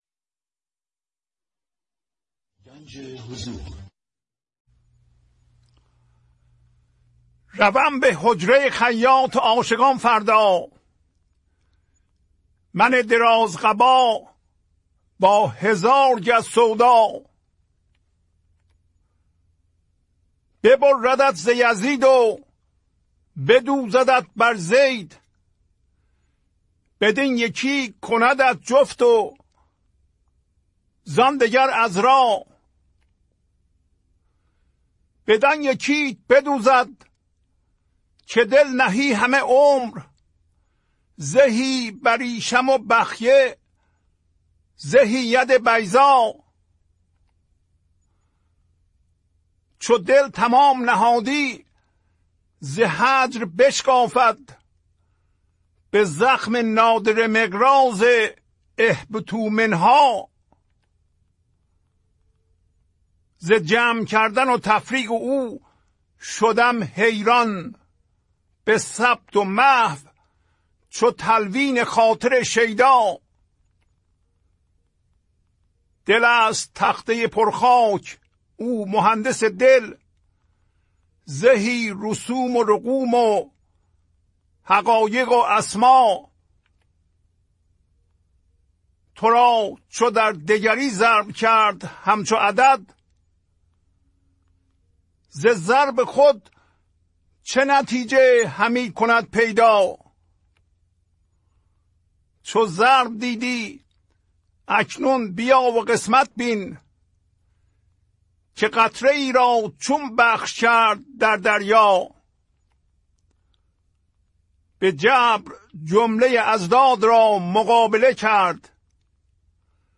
خوانش تمام ابیات این برنامه
1007-Poems-Voice.mp3